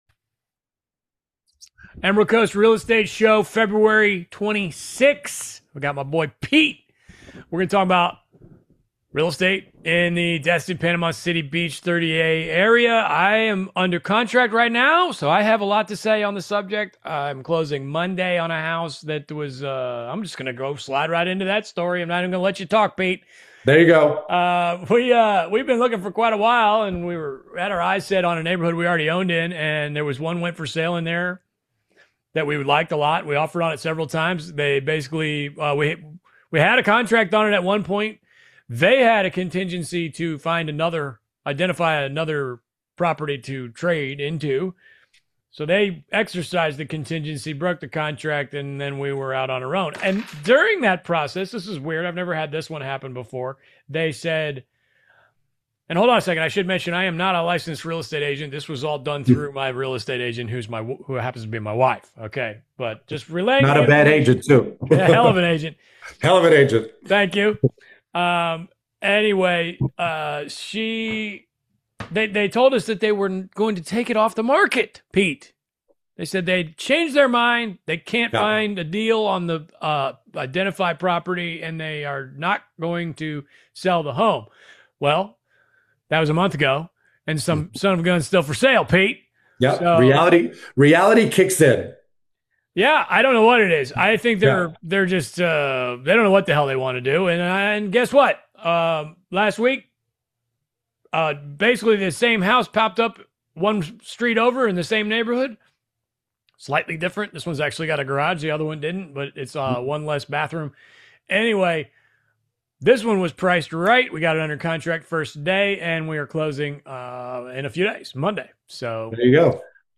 Market Update